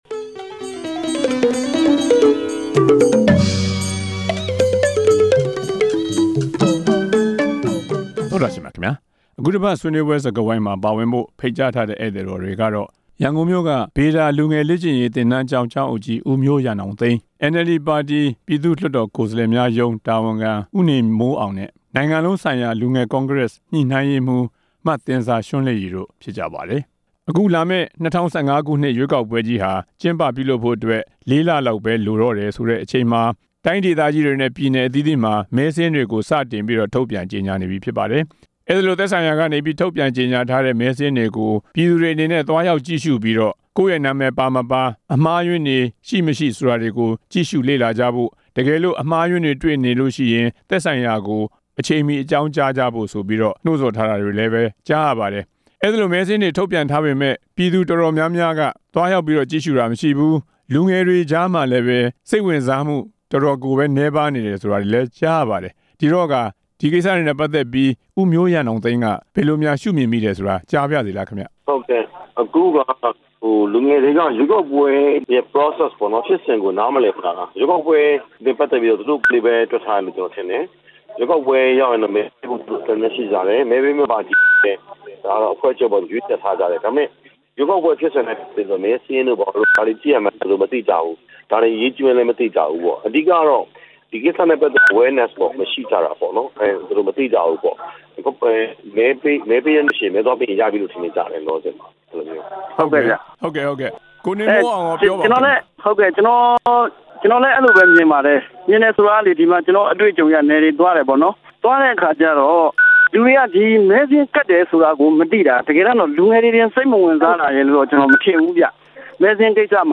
ဆွေးနွေးပွဲ စကားဝိုင်း